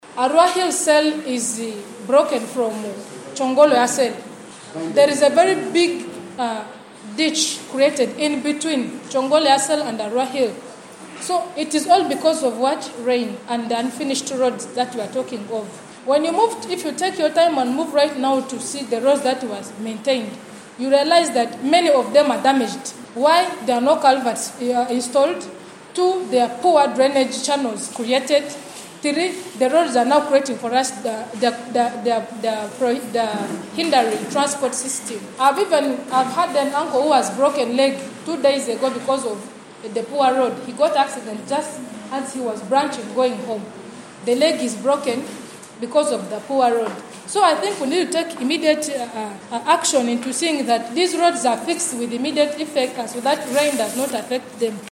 Additionally, Cynthia Asianzu, the female youth counselor for Arua Central, drew attention to the state of existing roads in the area. She stressed the urgency of addressing the dilapidated roads, highlighting the discomfort and inconvenience they cause to the residents.